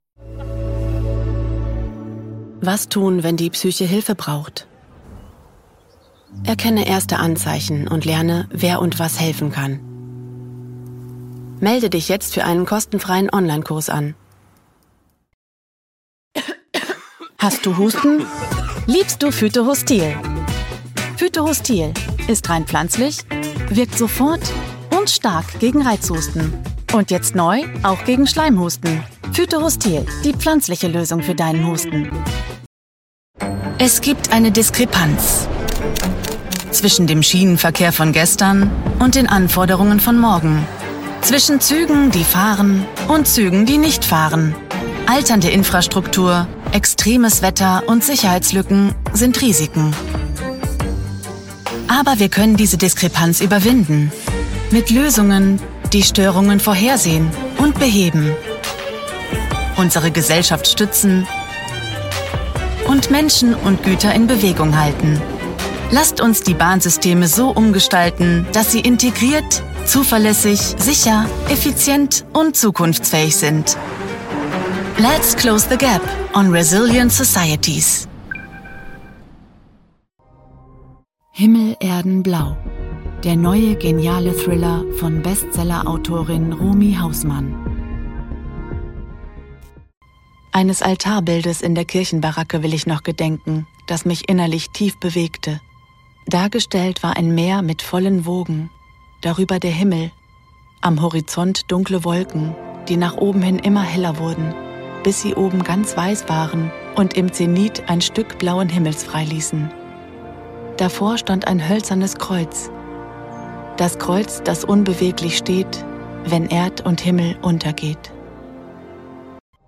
dunkel, sonor, souverän, hell, fein, zart
Mittel minus (25-45)
Eigene Sprecherkabine
Referenzen-Mix
Commercial (Werbung), Doku